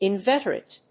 in・vet・er・ate /ɪnvéṭərət, ‐trət/
• / ɪnvéṭərət(米国英語)